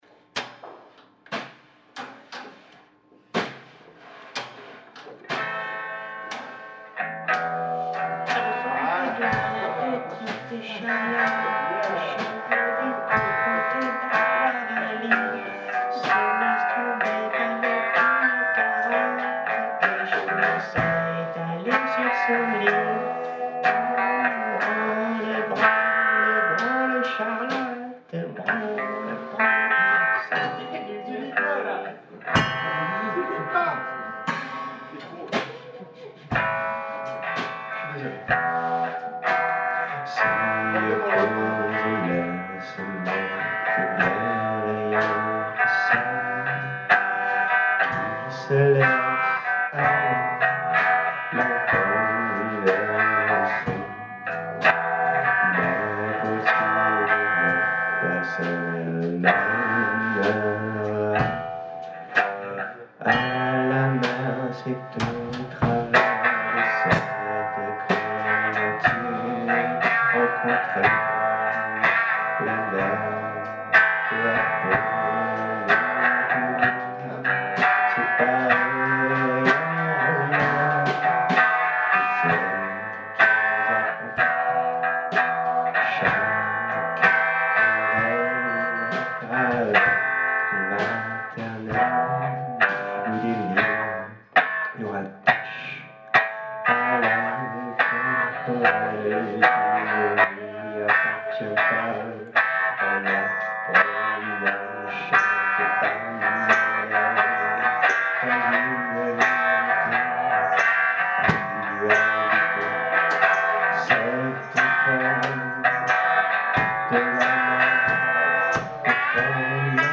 Concert de soutien à la revue Chutes
finchanson.mp3